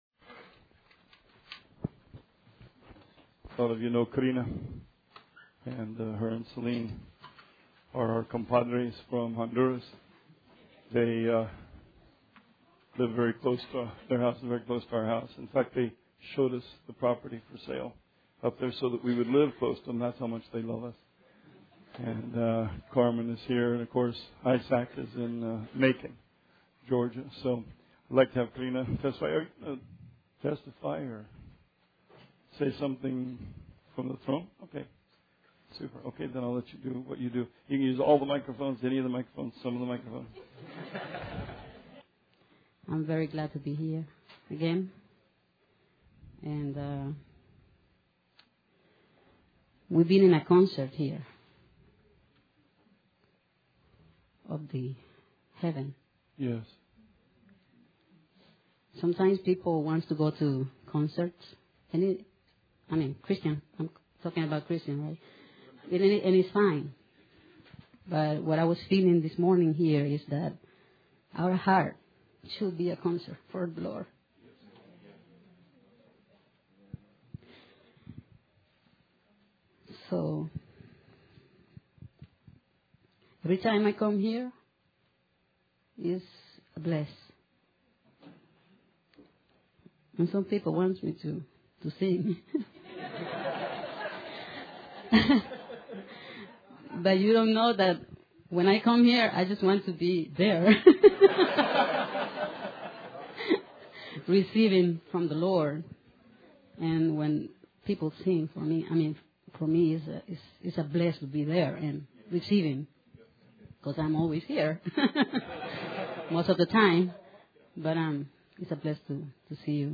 Sermon 10/9/16